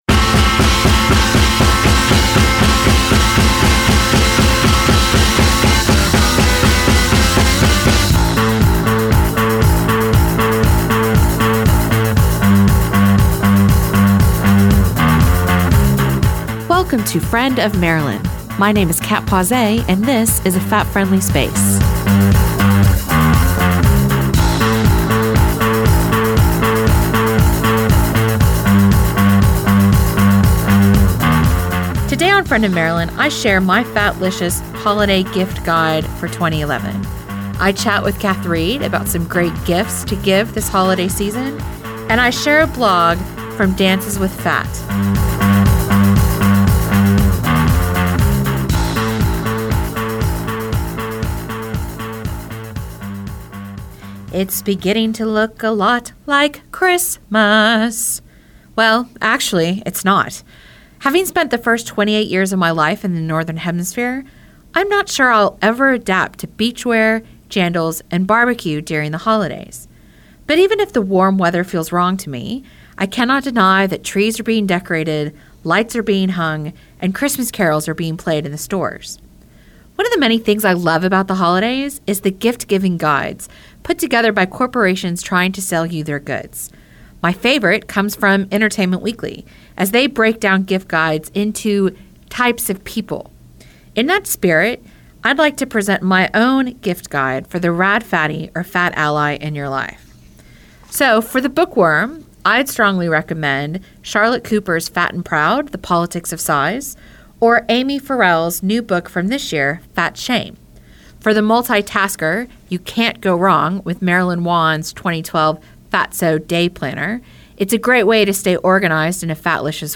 Friend of Marilyn was listened to around the world on multiple platforms and was broadcast locally by Manawatū People’s Radio online and on 999AM. The music featured throughout the programme is sampled